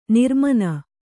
♪ nirmana